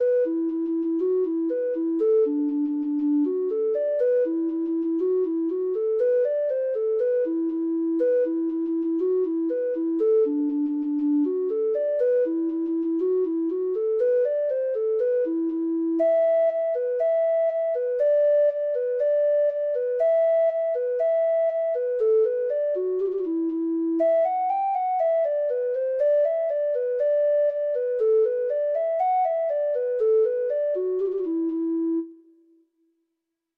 Treble Clef Instrument version
Traditional Music of unknown author.
Reels